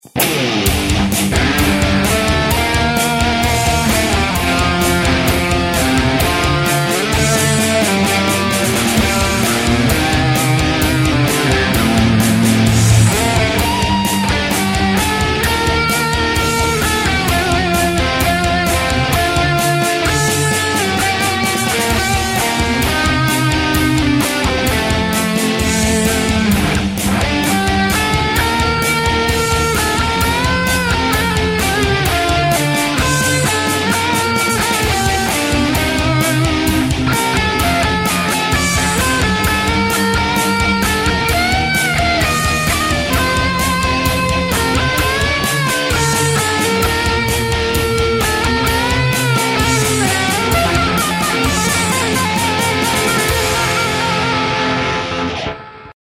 соло.
хорошо звучит, но барабаны помоему могли быть лучше.